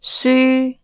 Cantonese[9] / s
[syː˥] 'book'